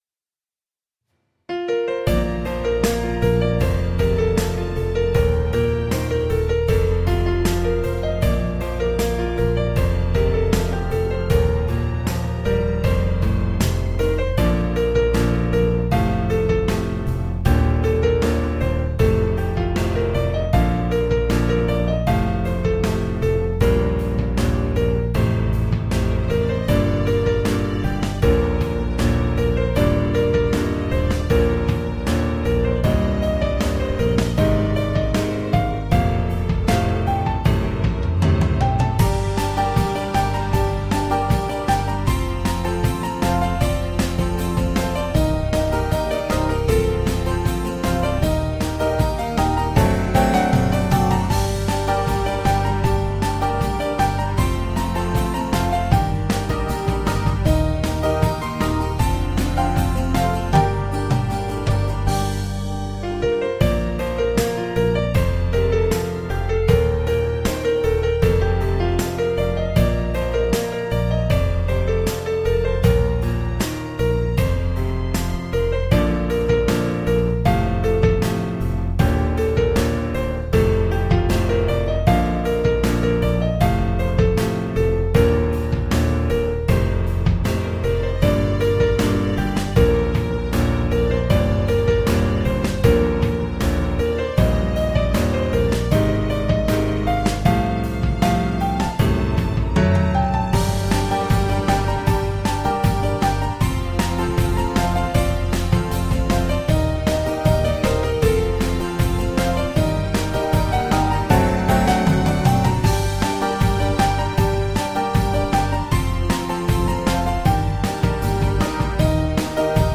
這首歌是為了練習鼓法而寫，對於一個完全沒碰過爵士鼓的人來說，自己編鼓算是頗大的挑戰